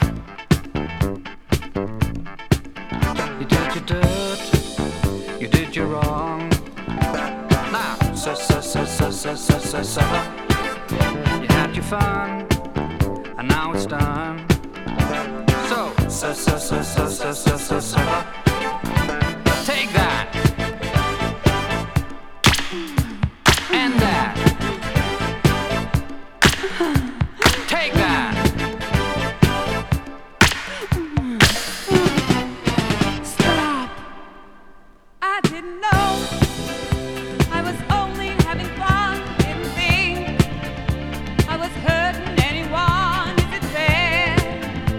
Disco, Funk, Soul　USA　12inchレコード　33rpm　Stereo